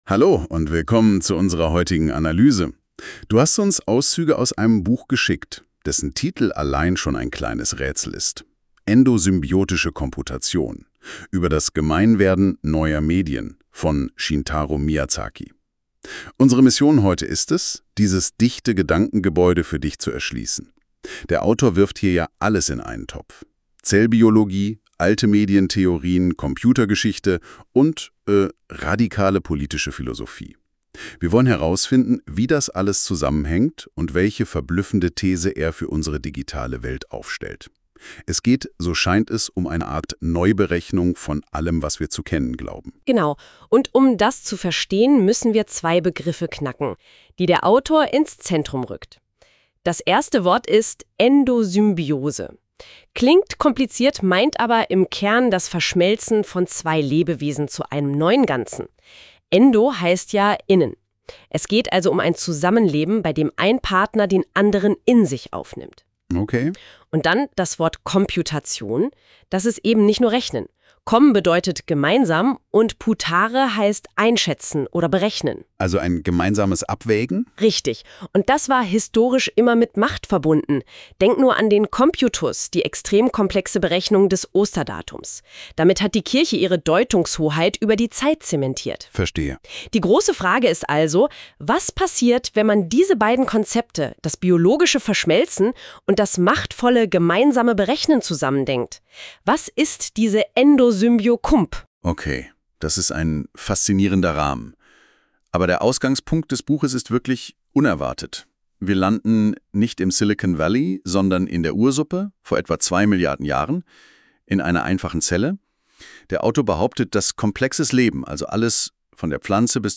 Endosymbiotische Komputation – eine maschinengenerierte Buchbesprechung 1, erster Überblick über alle drei Kapitel.